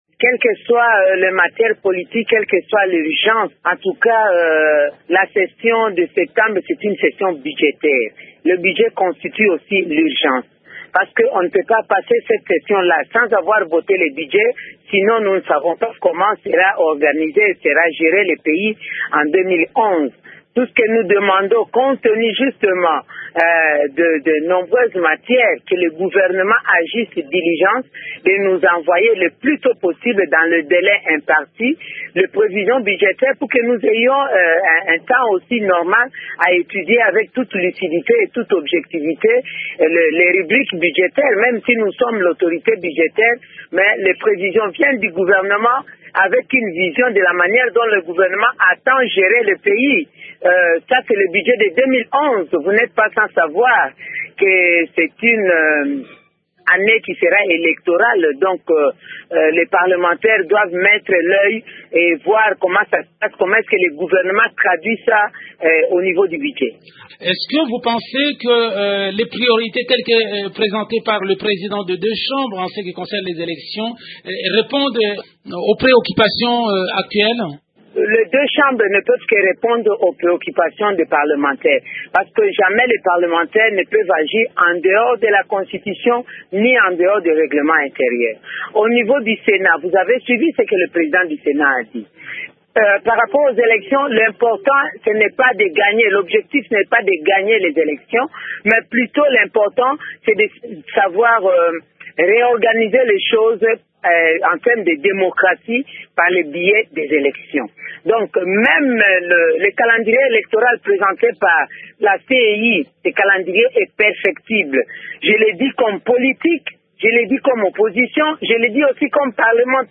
La sénatrice Eve Bazaiba s’exprime sur la rentrée parlementaire de septembre